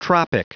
Prononciation audio / Fichier audio de TROPIC en anglais
Prononciation du mot : tropic